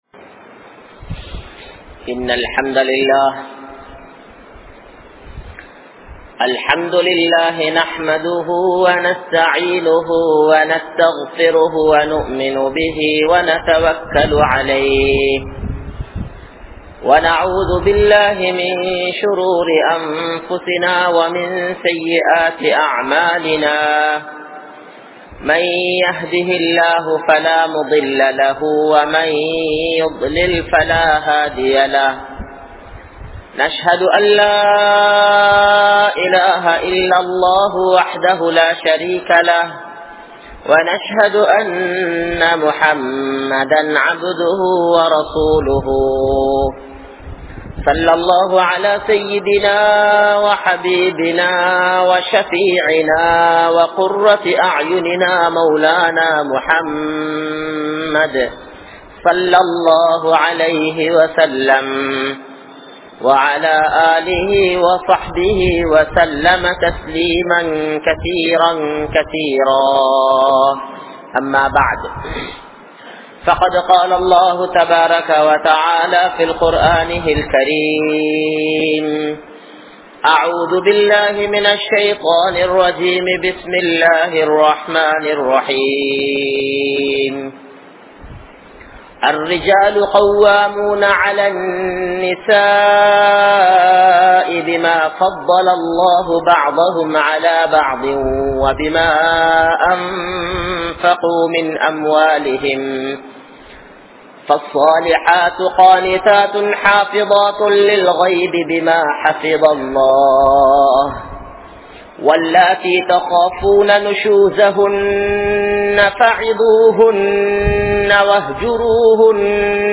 Kanavanin Kadamaihal (கனவனின் கடமைகள்) | Audio Bayans | All Ceylon Muslim Youth Community | Addalaichenai